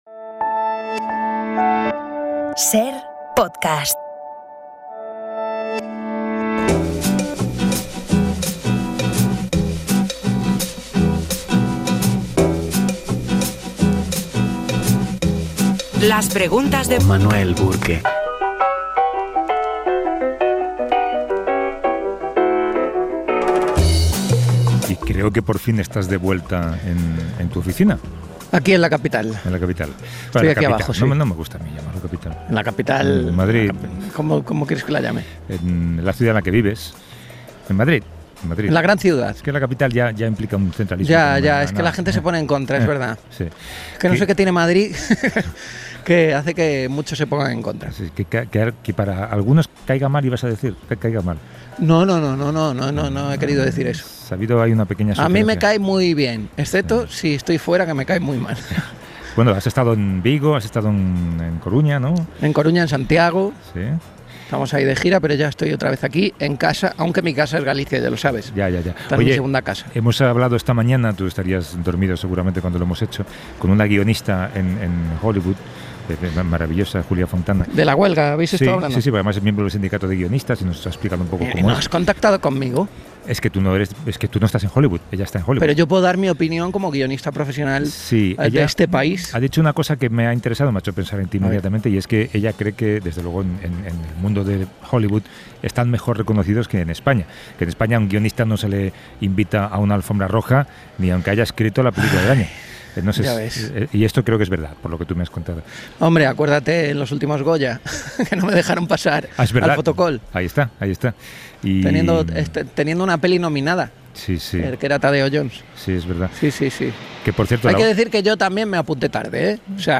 Manuel Burque pregunta por la calle por la polémica de este tipo de establecimientos.